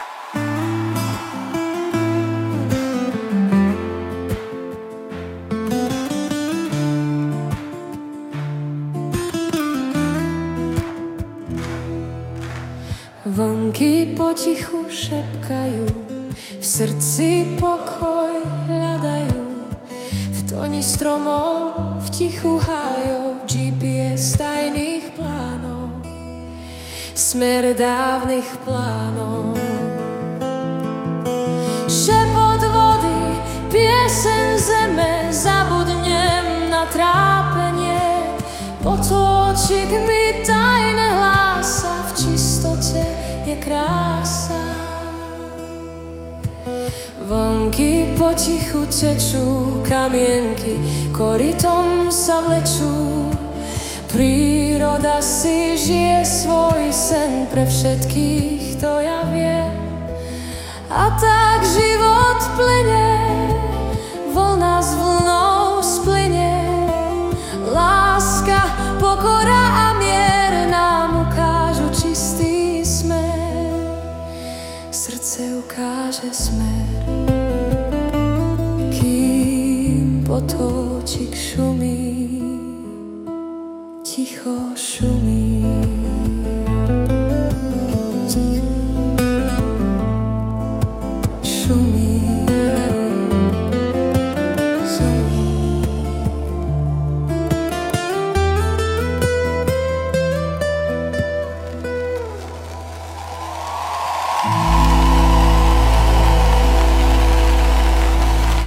Hudba a spev AI
Balady, romance » Romantické